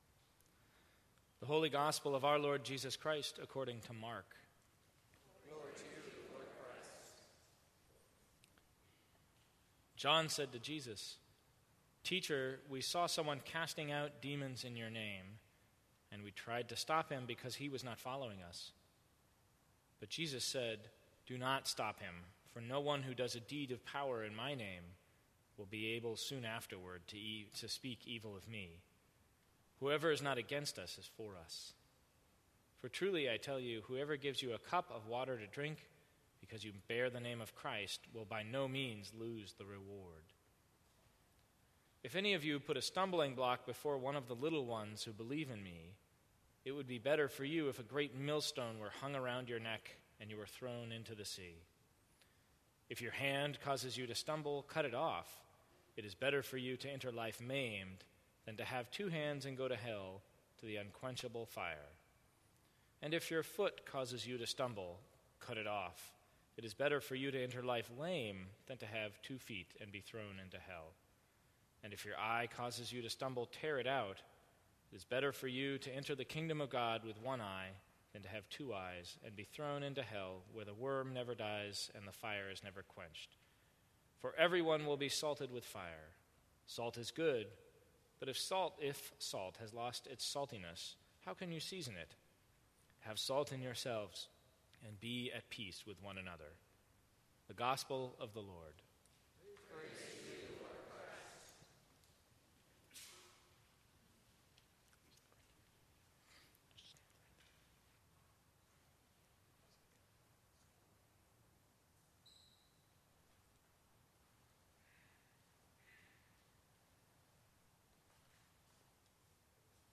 Sermons from St. Cross Episcopal Church Is God at work in more ways than you think?